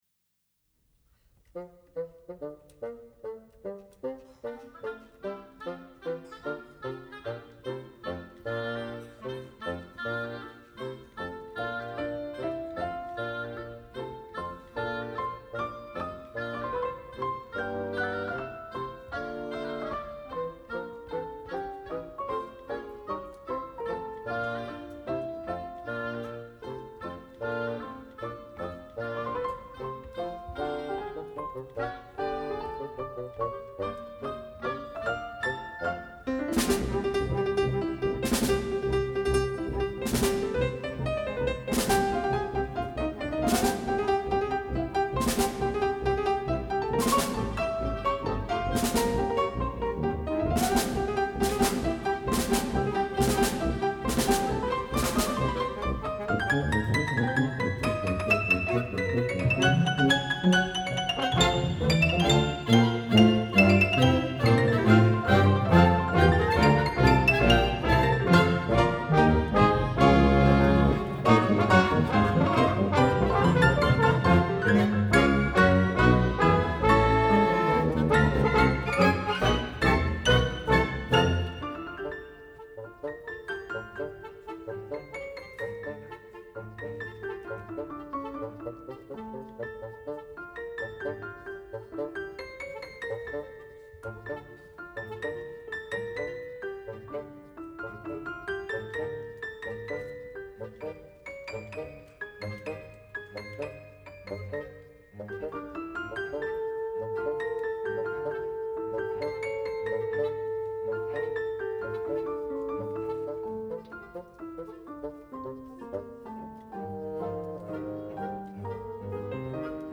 He did a great job recording this live performance for members and audience enjoyment.
Shostakovich Piano Concerto No. 2 in F major, Op. 102
piano